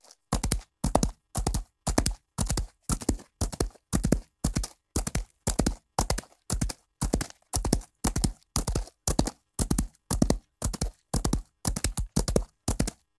horse_gallop_dirt.ogg